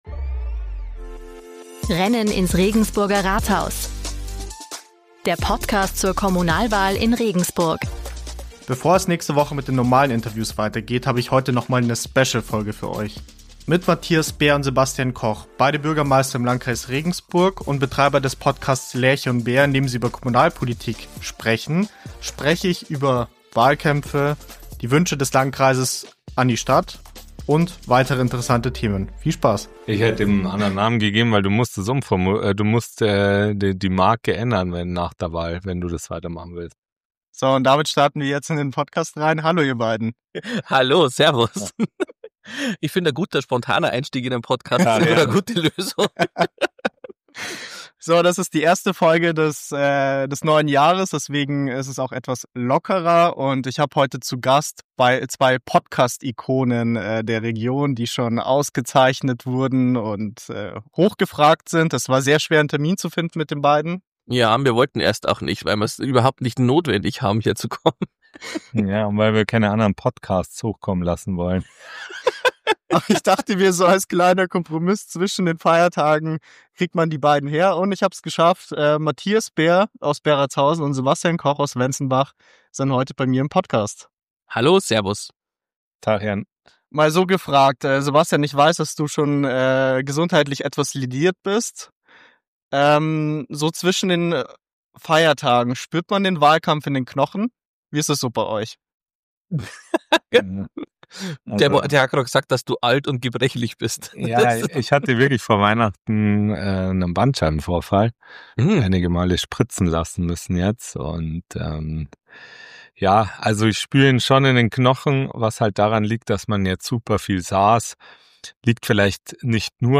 Beschreibung vor 3 Monaten In dieser Sonderfolge von Rennen ins Regensburger Rathaus sprechen Matthias Beer und Sebastian Koch – beide amtierende Bürgermeister im Landkreis Regensburg und selbst Podcastmacher – über Kommunalwahlkämpfe abseits der Großstadt, politische Realität zwischen Bürgernähe und Finanzdruck sowie das Verhältnis von Stadt und Landkreis. Es geht um Wahlkampf jenseits von Hochglanz, um knapper werdende Haushalte, um kleine Entscheidungen mit großer Wirkung – und um die Frage, was sich der Landkreis tatsächlich von der Stadt Regensburg wünscht.